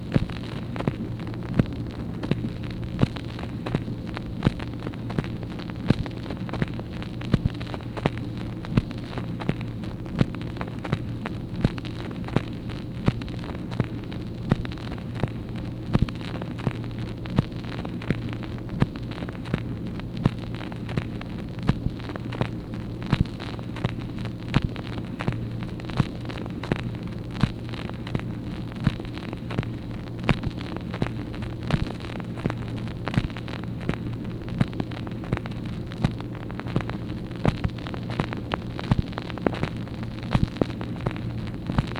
MACHINE NOISE, April 28, 1964
Secret White House Tapes | Lyndon B. Johnson Presidency